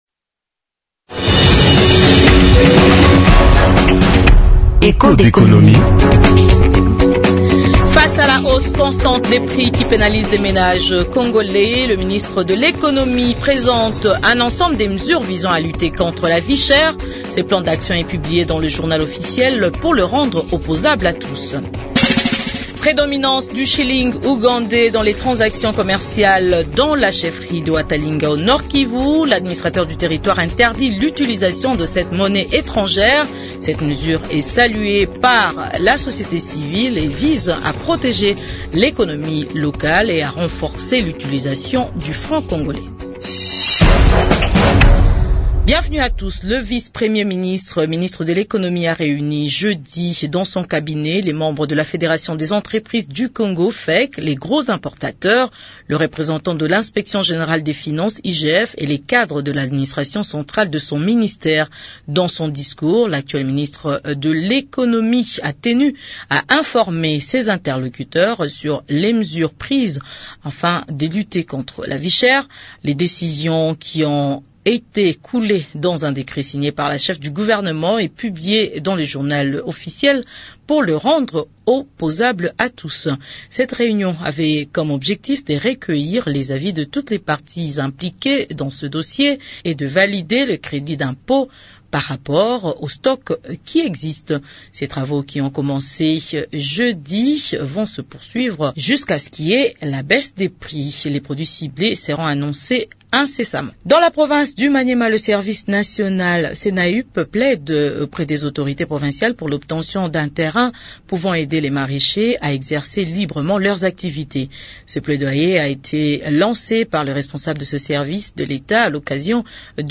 Autres sujets du magazine Echos d’économie de ce vendredi 22 novembre 2024 :